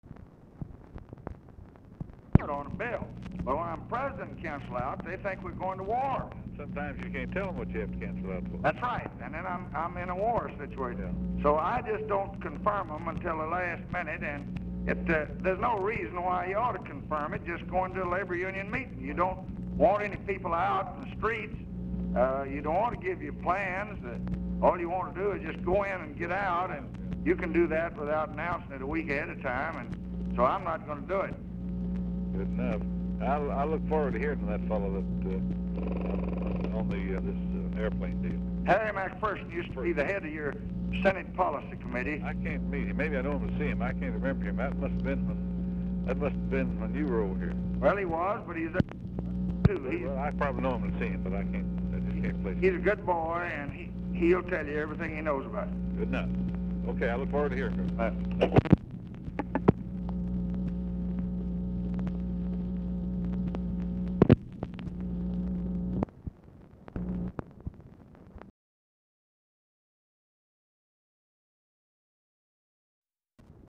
Telephone conversation # 10785, sound recording, LBJ and EDWARD LONG, 9/16/1966, 11:51AM
Format Dictation belt
Location Of Speaker 1 Oval Office or unknown location